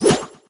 solgrenade01.ogg